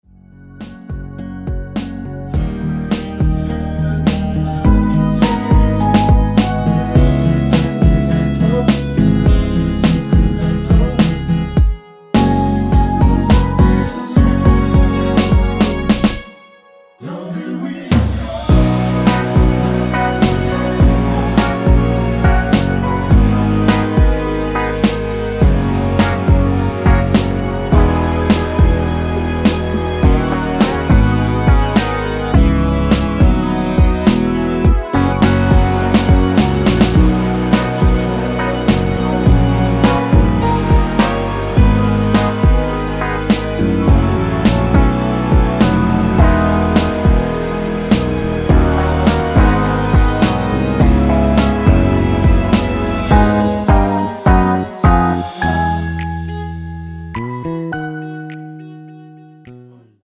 원키에서(-3)내린 코러스 포함된 MR입니다.
앞부분30초, 뒷부분30초씩 편집해서 올려 드리고 있습니다.
중간에 음이 끈어지고 다시 나오는 이유는